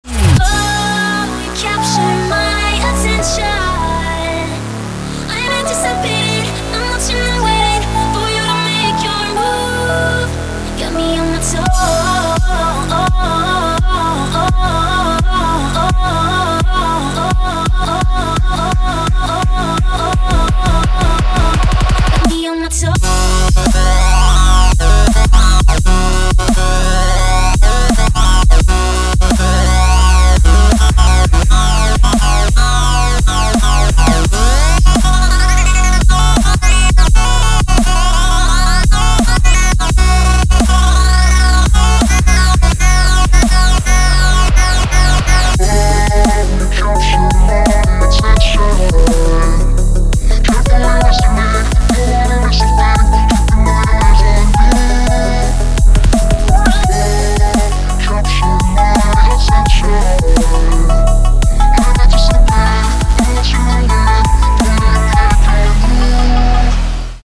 • Качество: 192, Stereo
мужской голос
женский вокал
Electronic
Bass
Стиль: drumstep